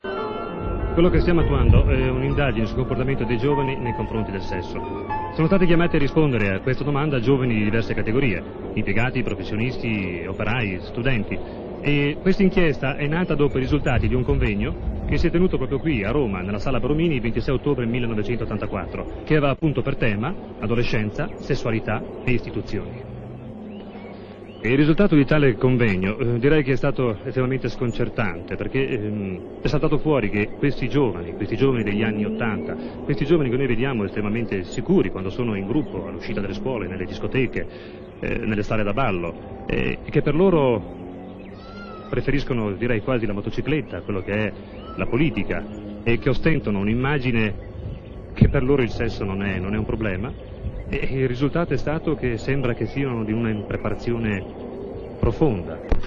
in cui interpreta lo speaker.